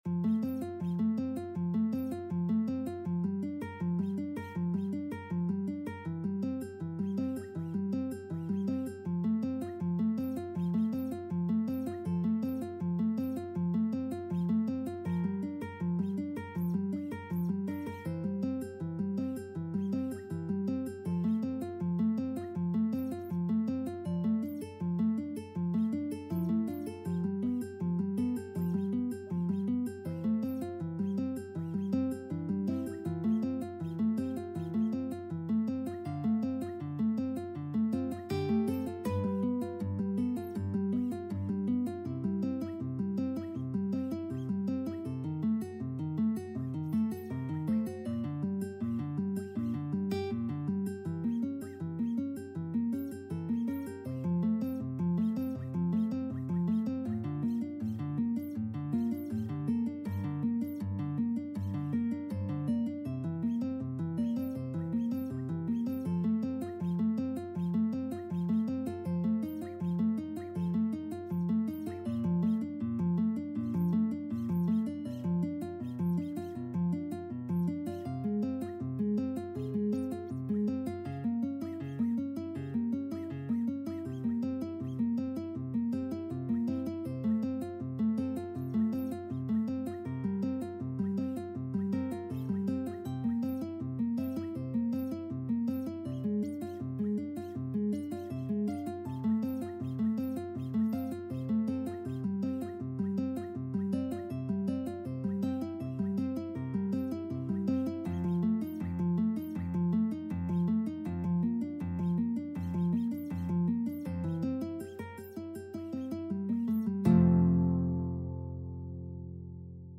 4/4 (View more 4/4 Music)
Arrangement for Guitar Duet
Classical (View more Classical Guitar Duet Music)